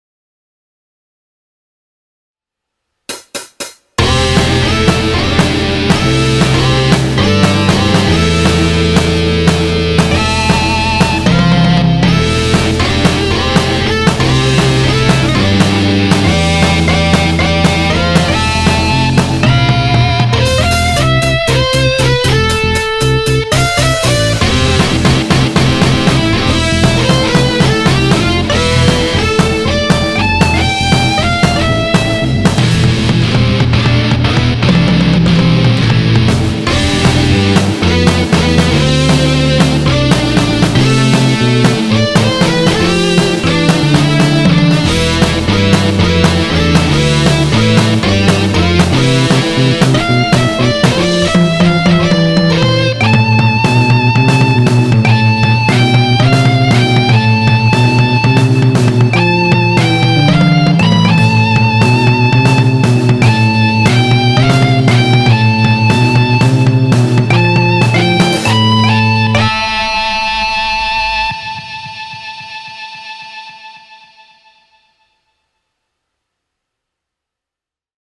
ショートロック